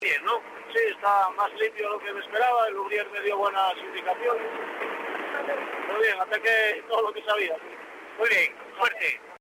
Declaraciones de los pilotos, cortesía de Cadena Dial: